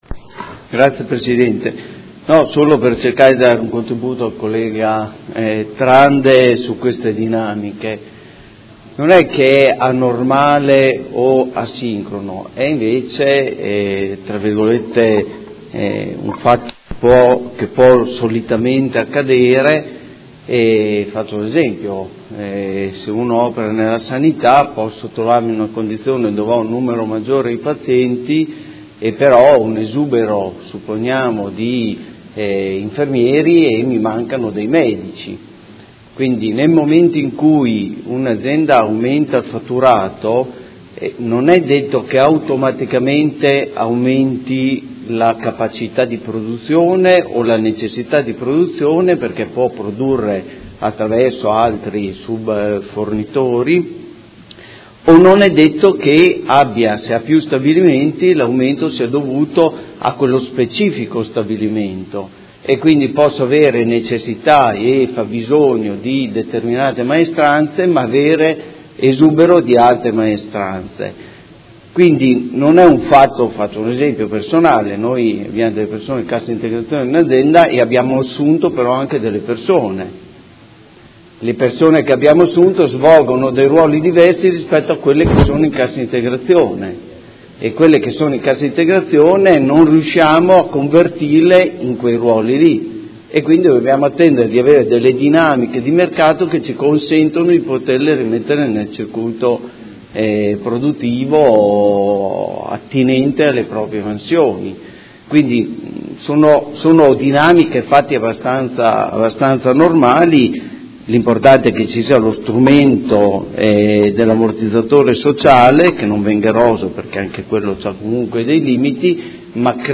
Antonio Montanini — Sito Audio Consiglio Comunale
Dibattito su interrogazione dei Consiglieri Malferrari, Trande e Bortolamasi (P.D.) avente per oggetto: Cassa integrazione alla Maserati di Modena